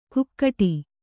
♪ kukkaṭi